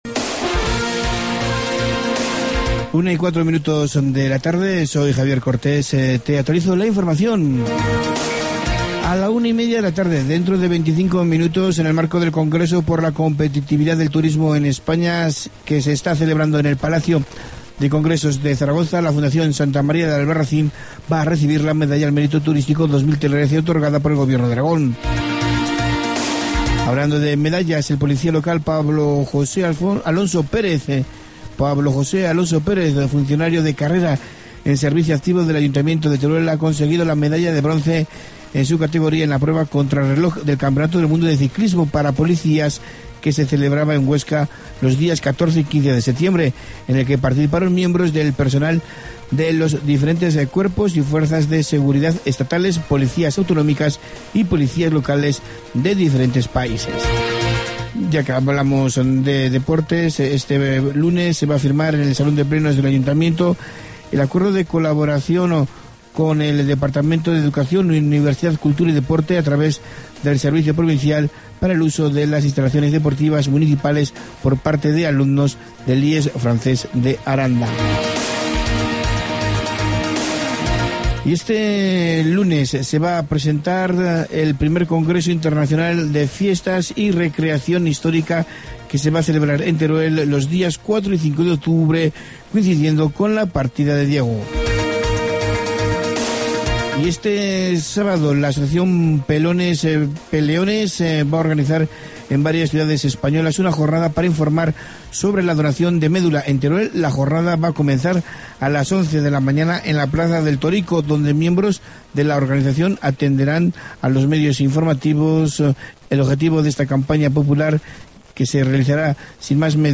Incluye el programa de música clásica DA COPE.